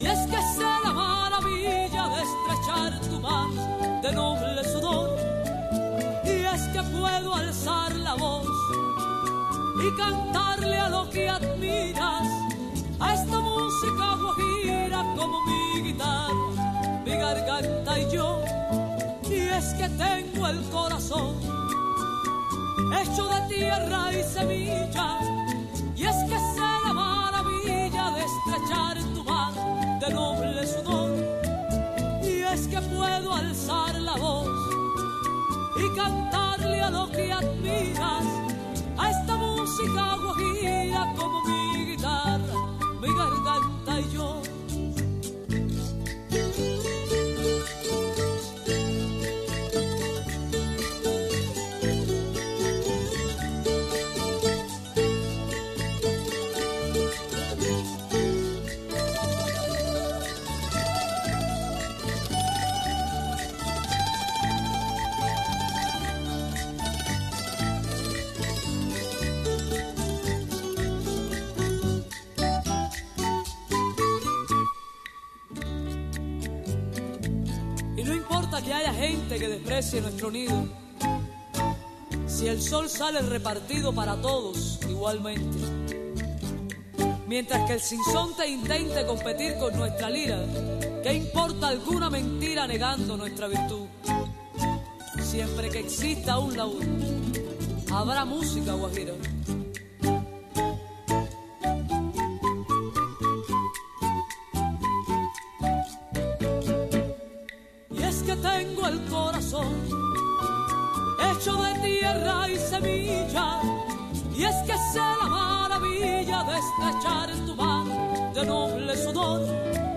Una hora con temas para el campesino, entrevistas y música.